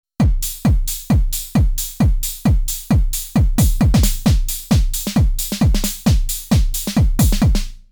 sy bits one combined with carbon’s high end - doesn’t quite fit (i tuned them later to be a similar ‘chord’ but can’t record it right now, it was still not a super fit though)